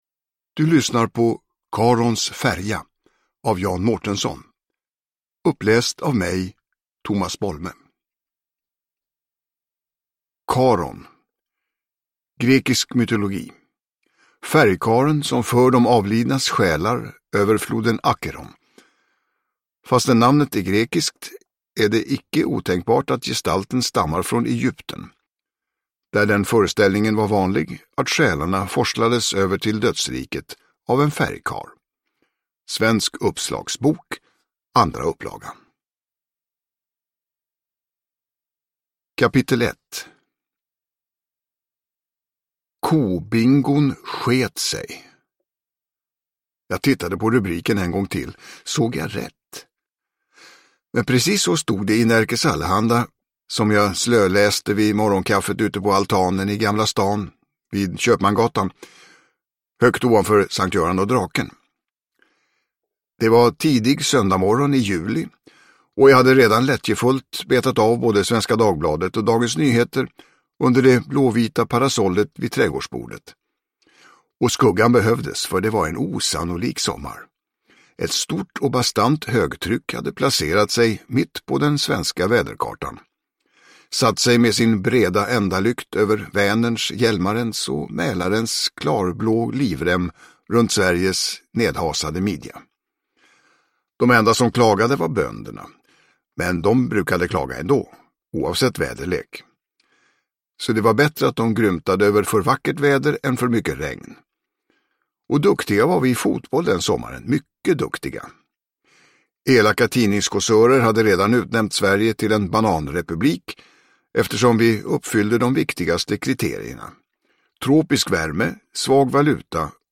Uppläsare: Tomas Bolme
Ljudbok